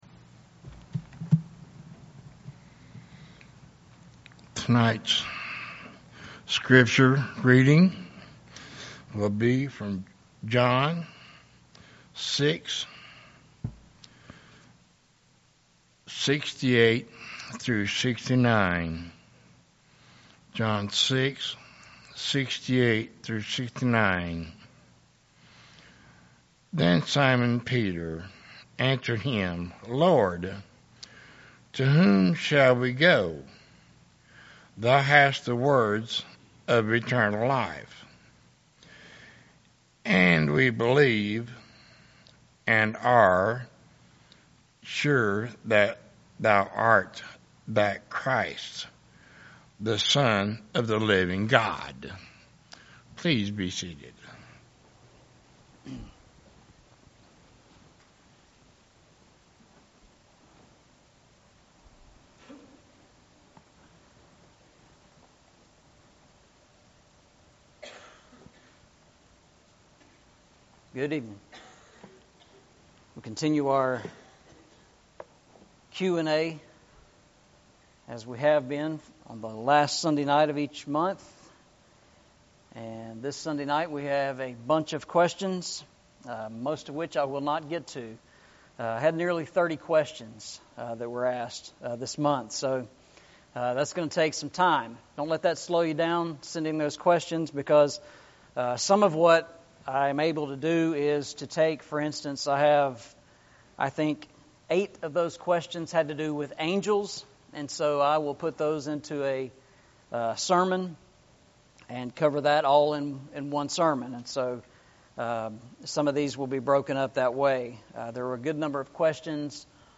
Eastside Sermons Passage: John 6:68-69 Service Type: Sunday Evening « Serving the Lord with Gladness Serving the Lord with Gladness